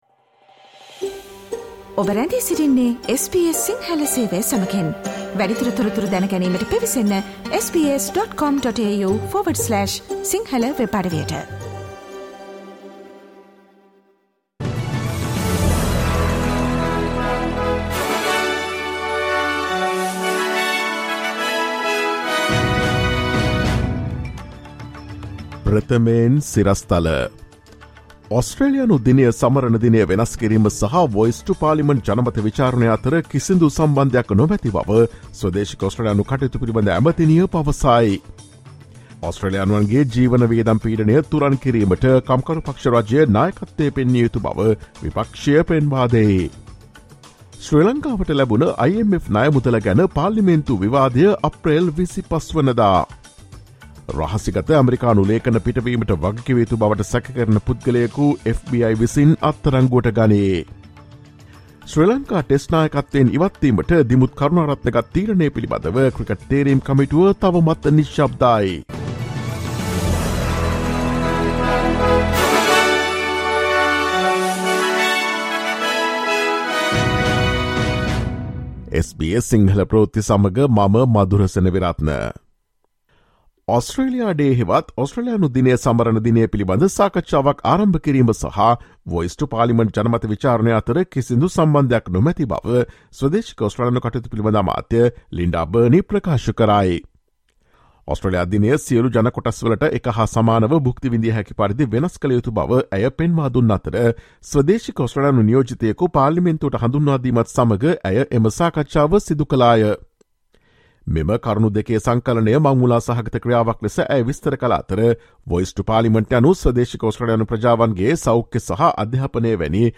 ඔස්ට්‍රේලියාවේ සහ ශ්‍රී ලංකාවේ නවතම පුවත් මෙන්ම විදෙස් පුවත් සහ ක්‍රීඩා පුවත් රැගත් SBS සිංහල සේවයේ 2023 අප්‍රේල් 14 වන දා සිකුරාදා වැඩසටහනේ ප්‍රවෘත්ති ප්‍රකාශයට සවන් දෙන්න.